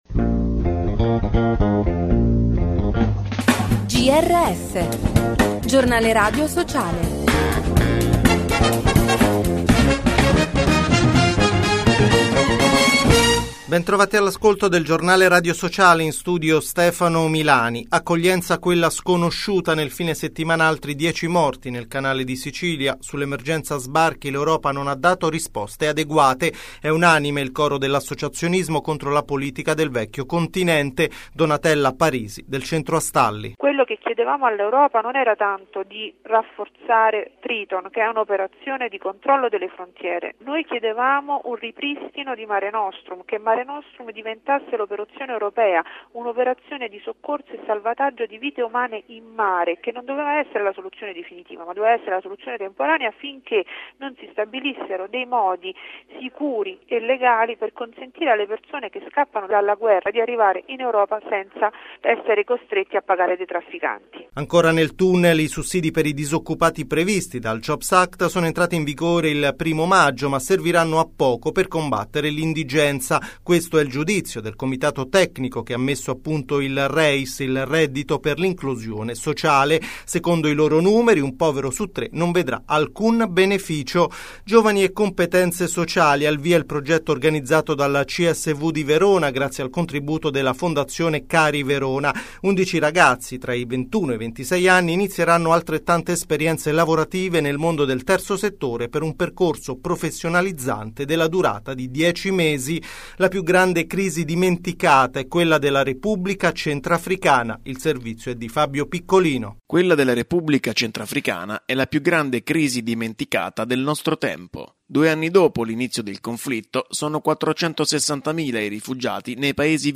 Vi proponiamo un’intervista del 2009 realizzata da Esperienza Italia al Cinema Massimo nel 148° anniversario dell’unità d’Italia